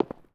Concret Footstep 04.wav